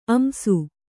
♪ amsu